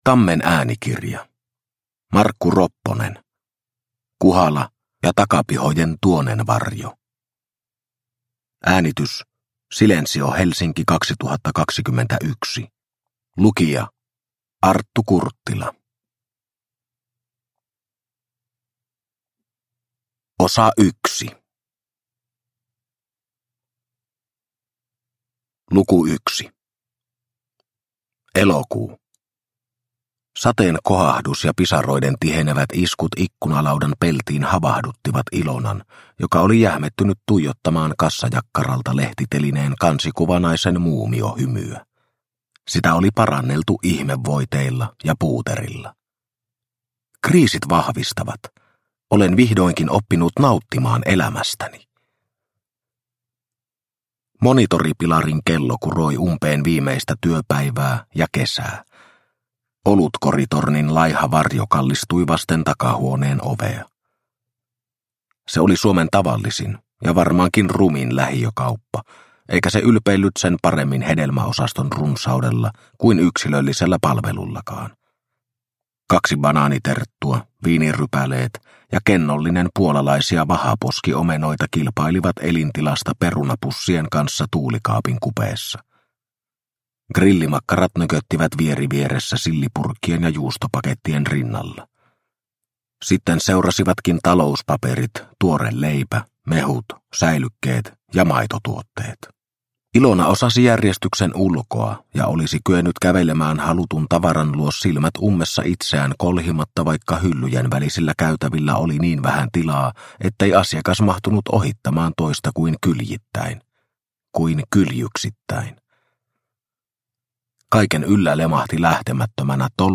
Kuhala ja takapihojen tuonenvarjo – Ljudbok – Laddas ner